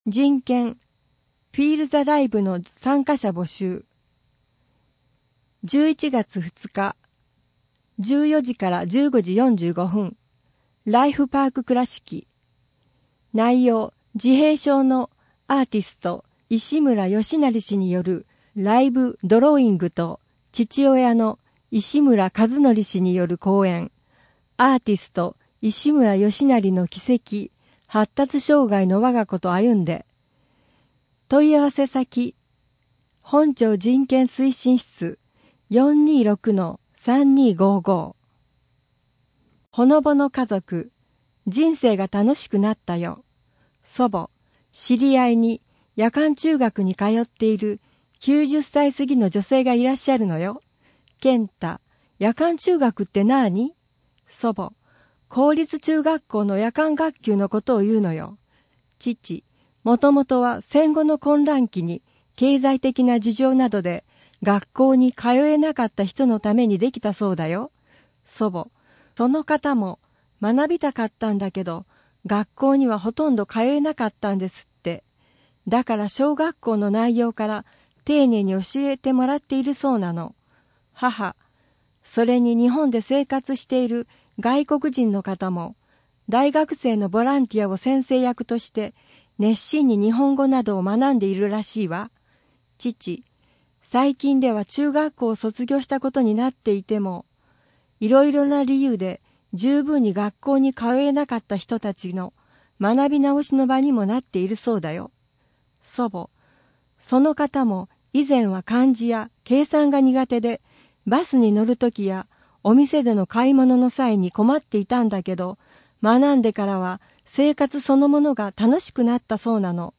2024年広報くらしき9月号（音訳版）